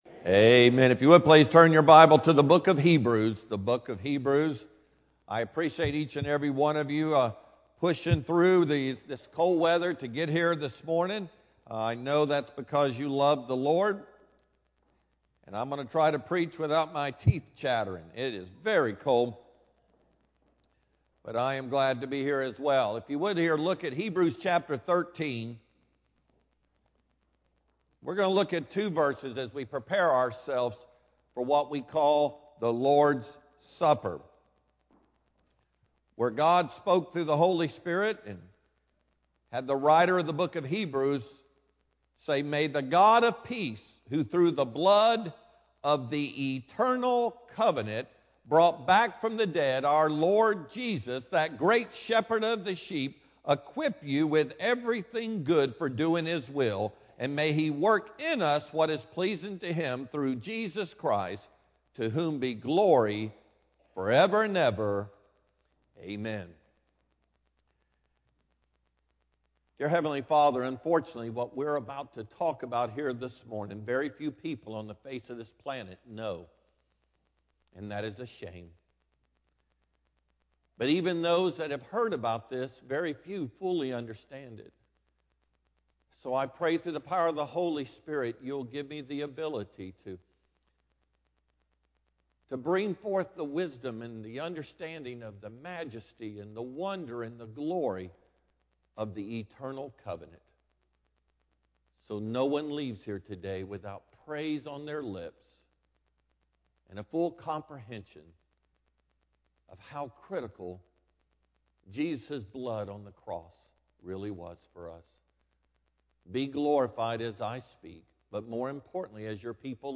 Sermon-2-15-AM-CD.mp3